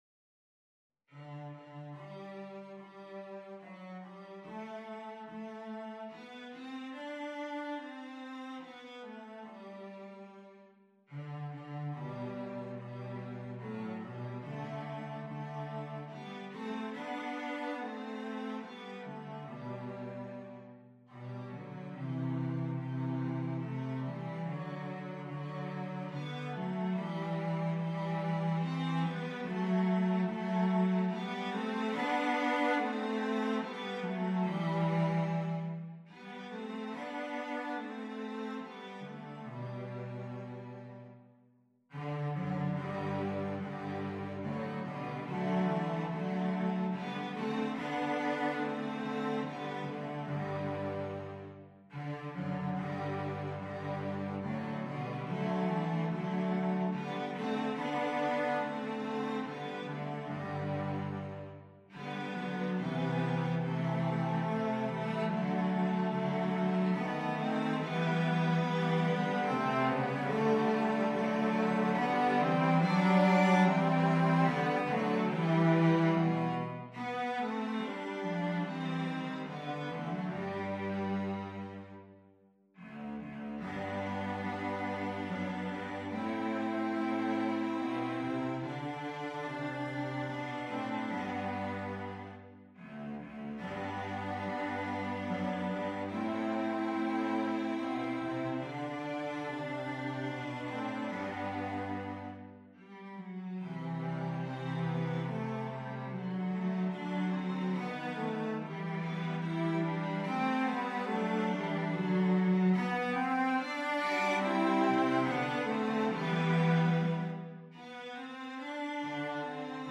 a tender Polish carol arranged for cello trio